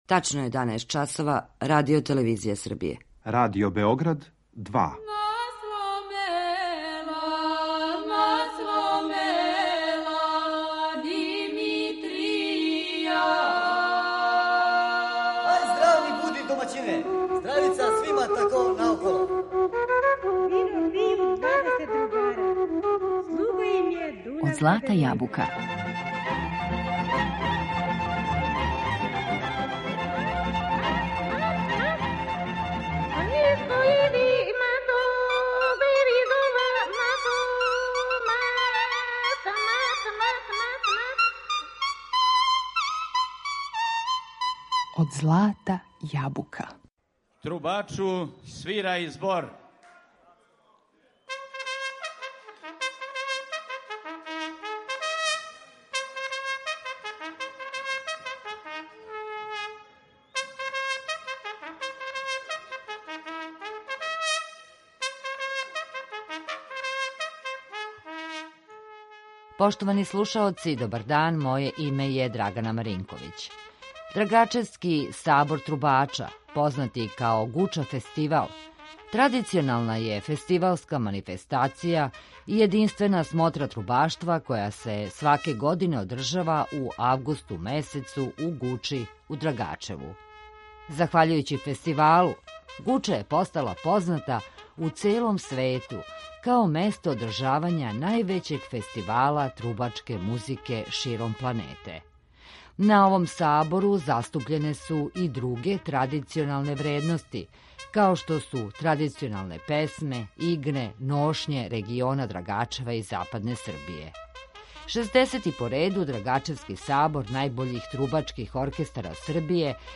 У данашњој емисији Од злата јабука водимо вас музиком и причом на финално такмичење најбољих оркестара Србије , на 60. Драгачевски Сабор трубача.